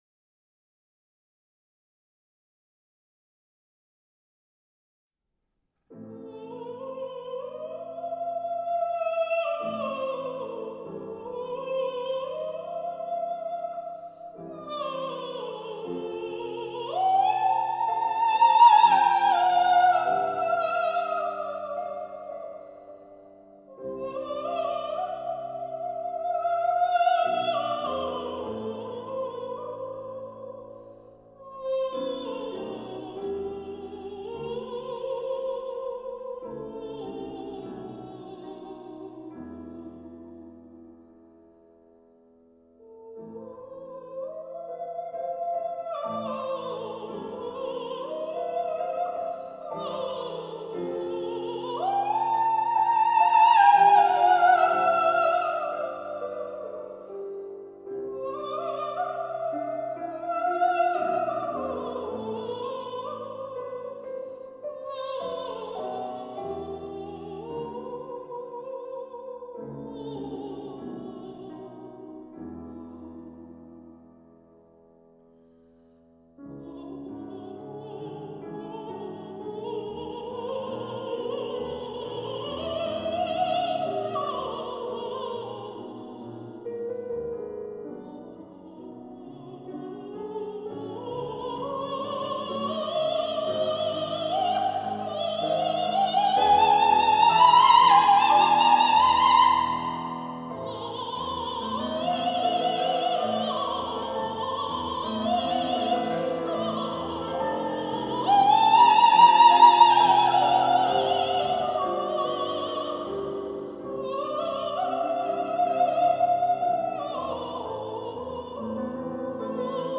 сопрано).  Концертмейстер